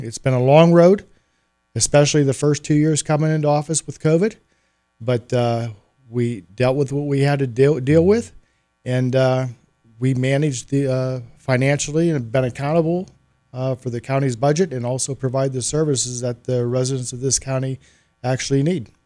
Commissioners Chairman Mike Keith and fellow Commissioner Robin Gorman appeared on Indiana in the Morning on WCCS to announce that both plan on running for a second term in office.